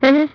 Sound_Effects
pong_v6.WAV